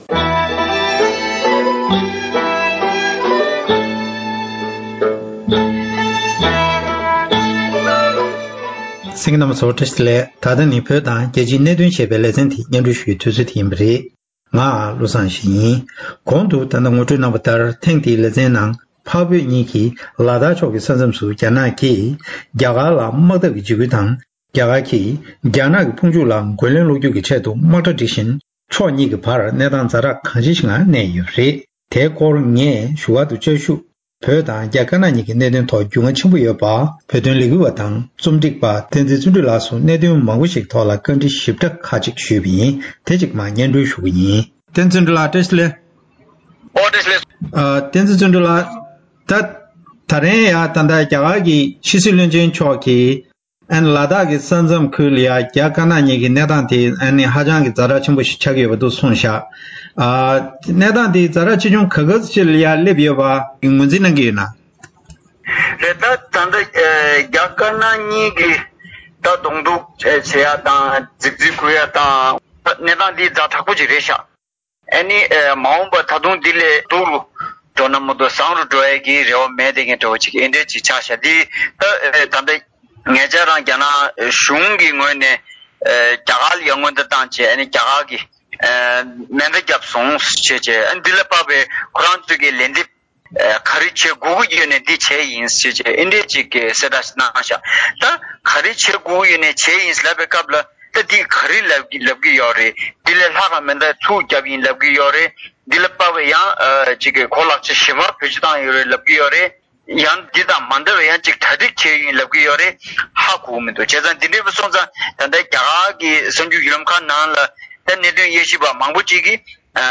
བཀའ་དྲིས་ཞུས་ཏེ་ཕྱོགས་སྒྲིག་དང་སྙན་སྒྲོན་ཞུས་པར་གསན་རོགས་ཞུ།།